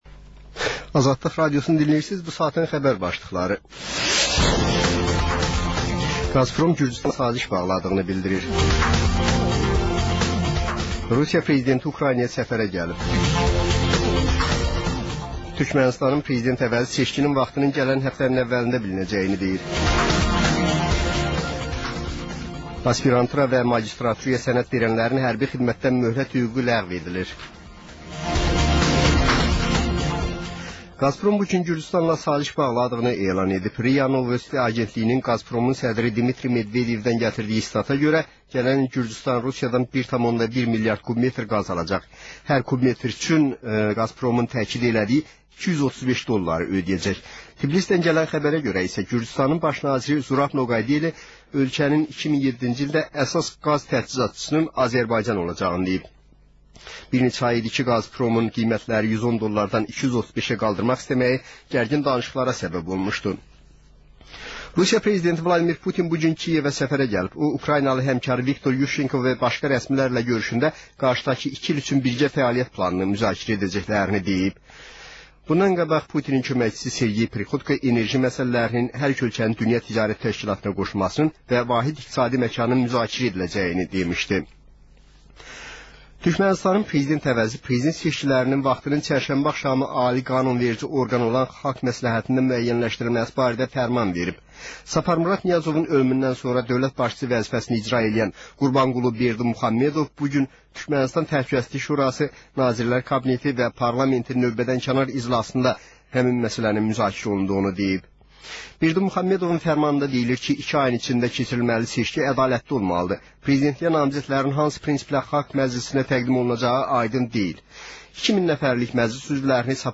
Həftənin aktual məsələsi barədə dəyirmi masa müzakirəsi